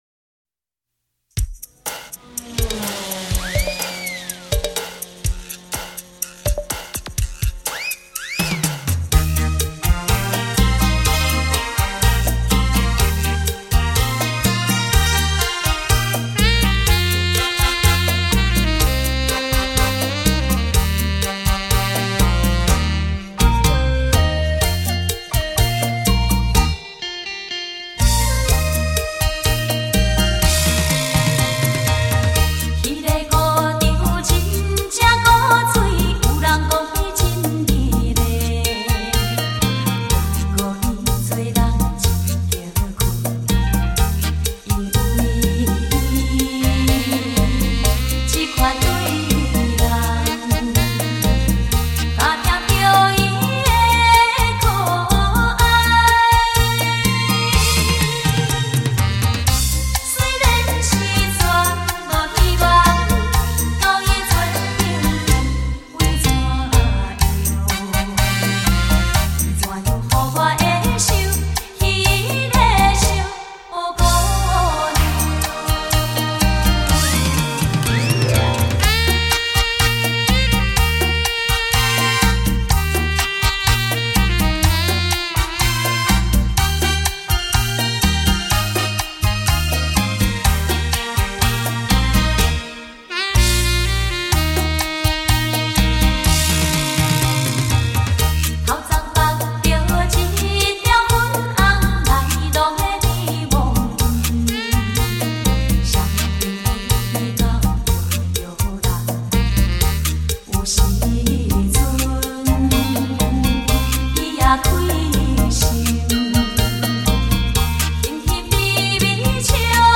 恰恰